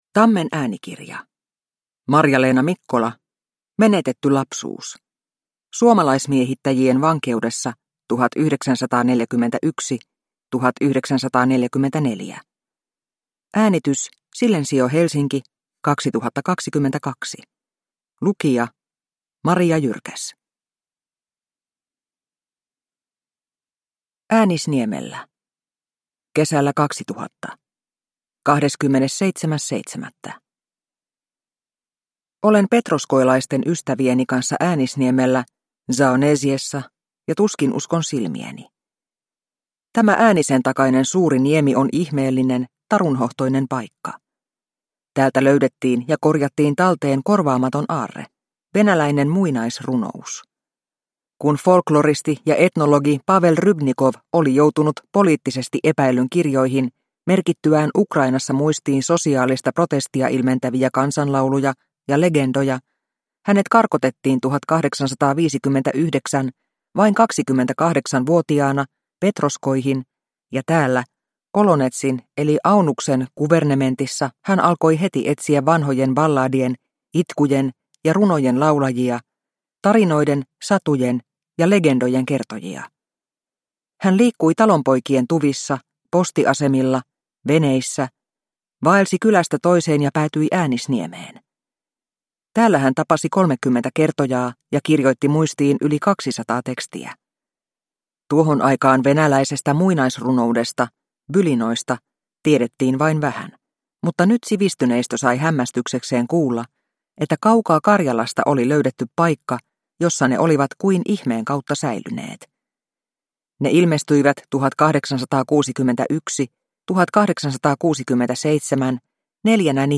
Menetetty lapsuus – Ljudbok – Laddas ner